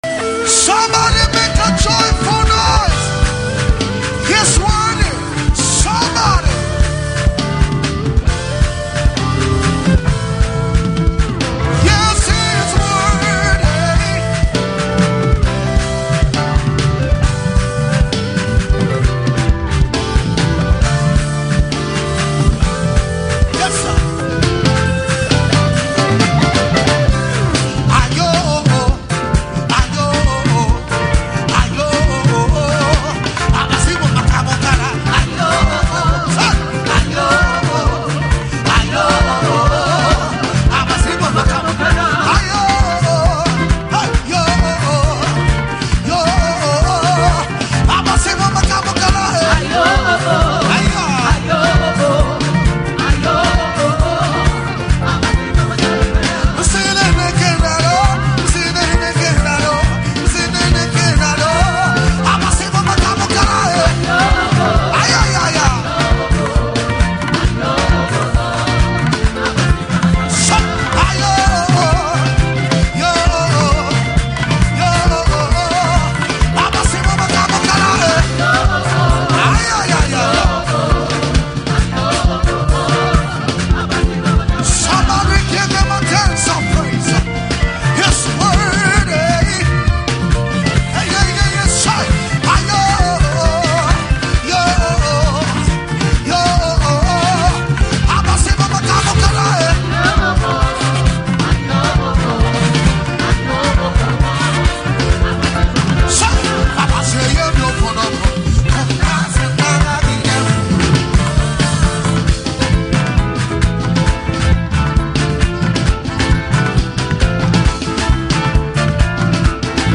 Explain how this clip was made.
during live service ministration.